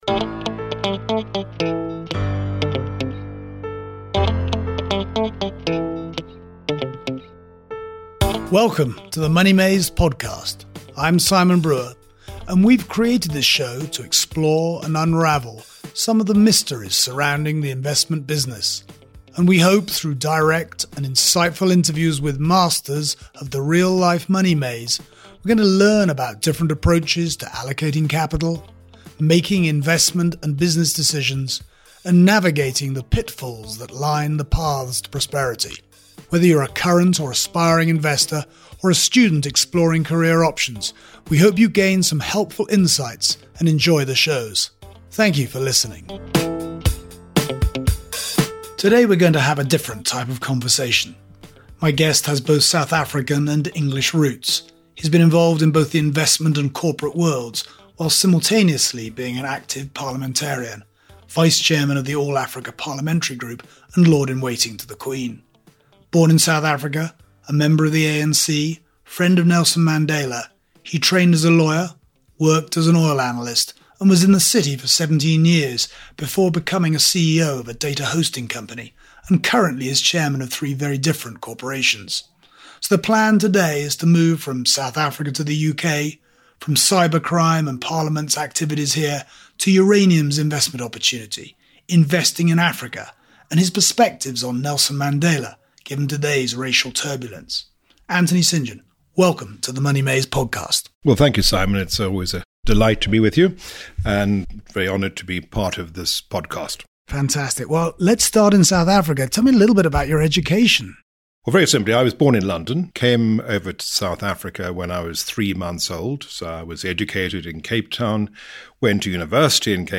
[REPLAY] Investing in Africa: A Conversation with Anthony St John, 22nd Baron St John of Bletso